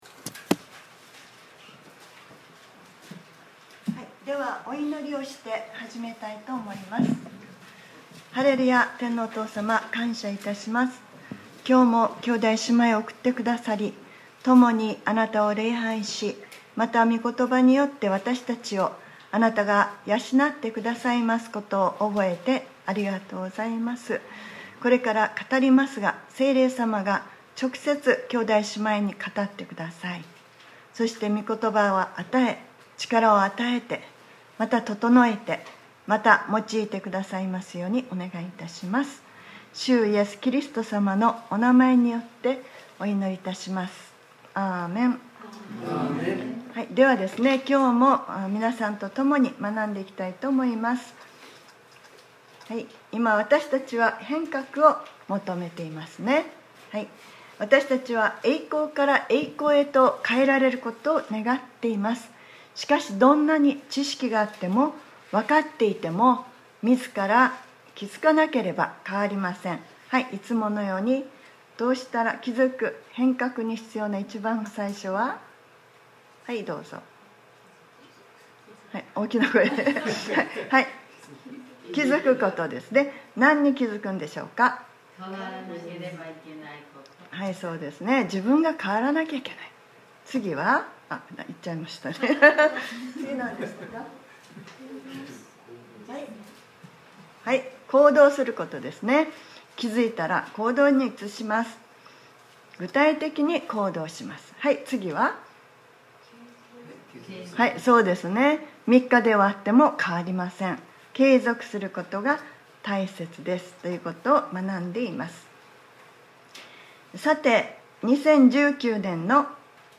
2019年08月25日（日）礼拝説教『ヴィジョン６：弟子の成長』